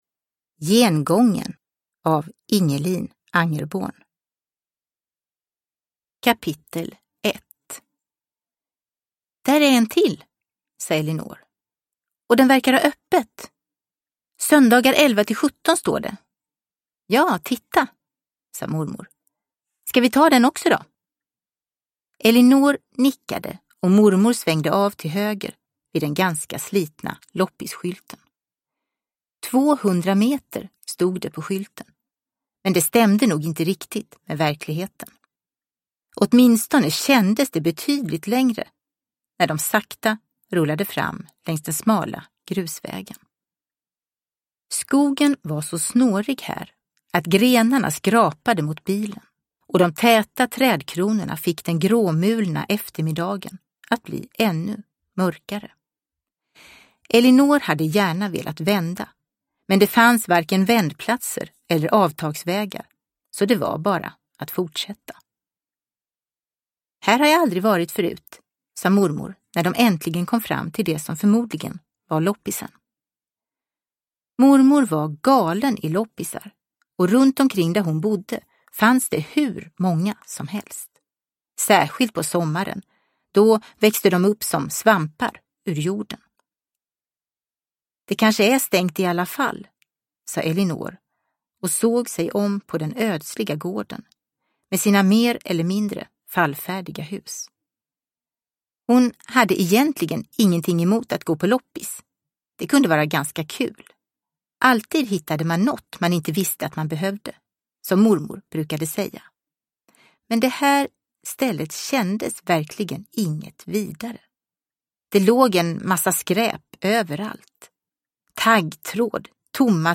Gengången – Ljudbok – Laddas ner